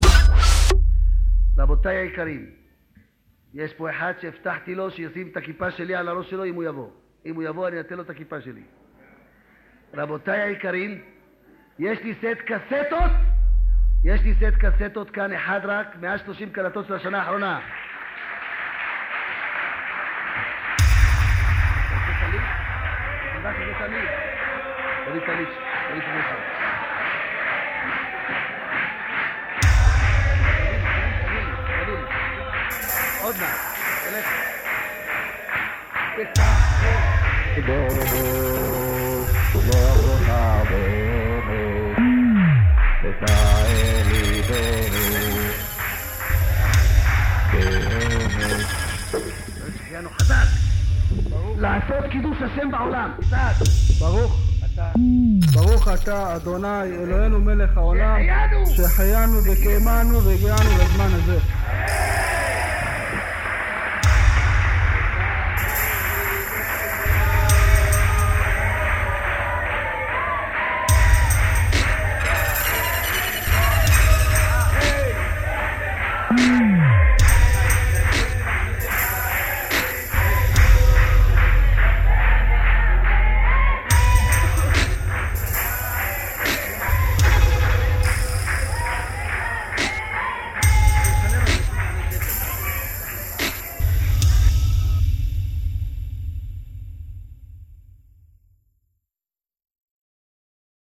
All of these pieces began with an object I found lying around my studio.
Hebrew (1.5 MB) - The vocals in this are from a cassette tape I got at a local restaurant... I have no idea what they're saying.
ics1.1-hebrew.mp3